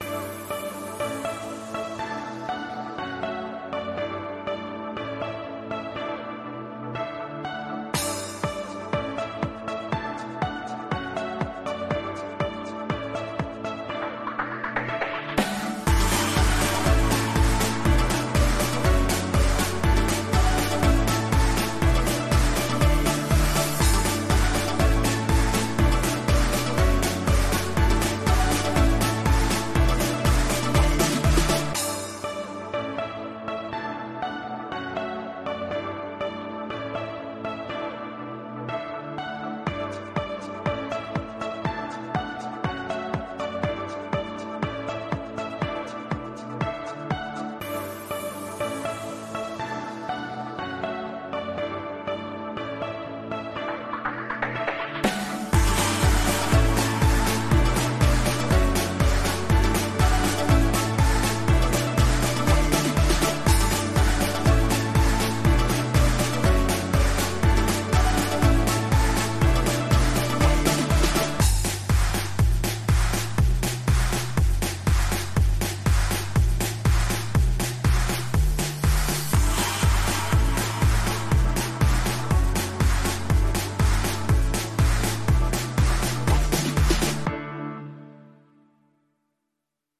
ElectroampDance_0324_2.mp3